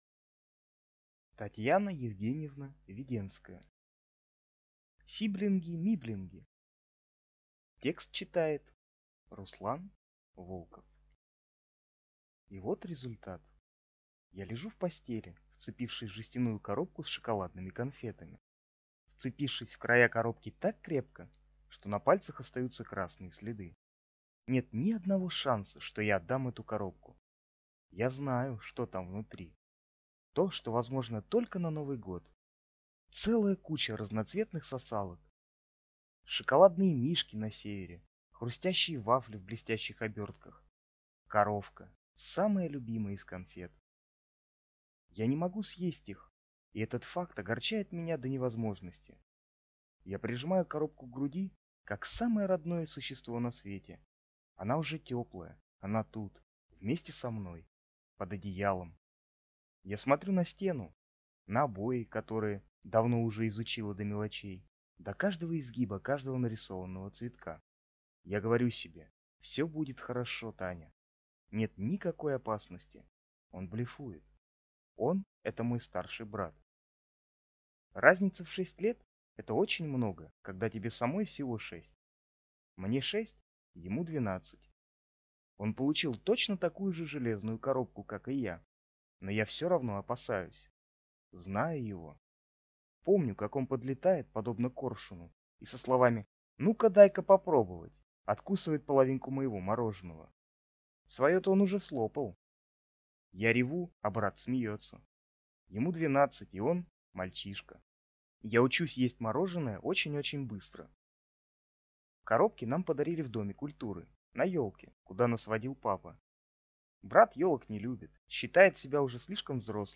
Аудиокнига Сиблинги-миблинги | Библиотека аудиокниг